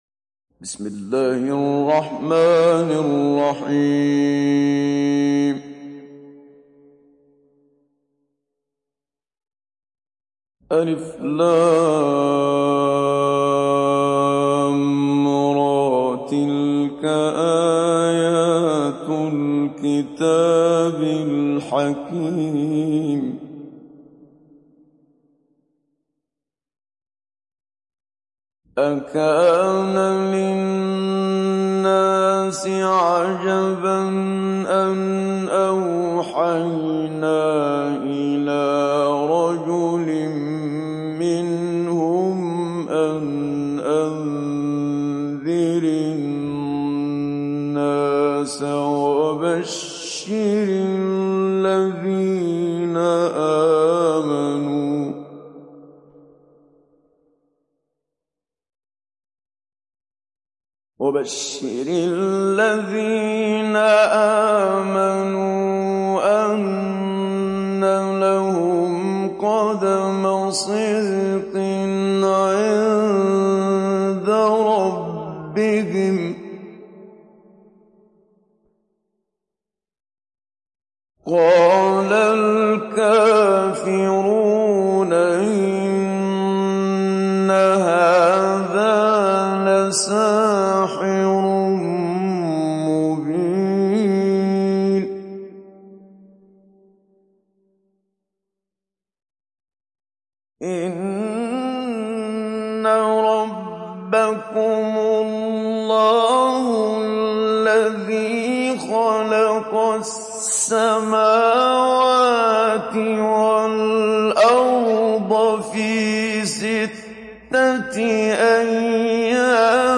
دانلود سوره يونس محمد صديق المنشاوي مجود